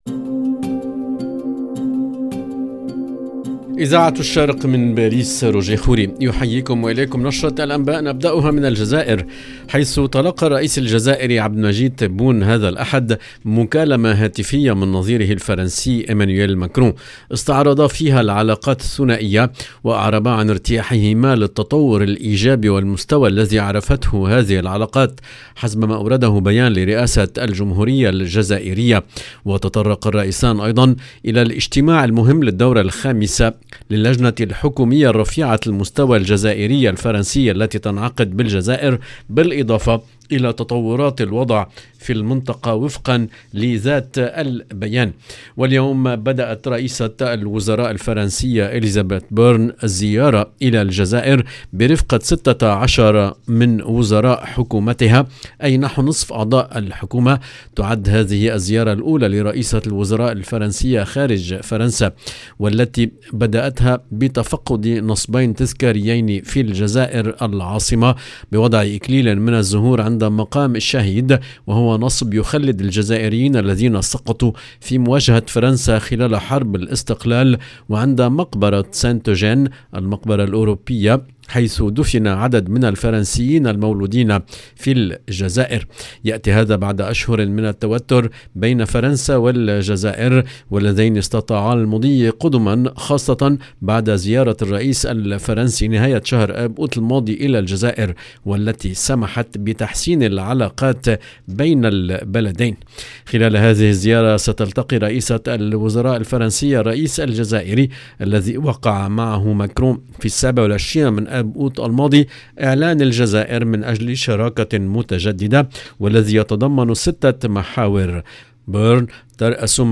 LE JOURNAL DU SOIR EN LANGUE ARABE DU 9/10/2022
EDITION DU JOURNAL DU SOR EN LANGUE ARABE DU 9/10/2022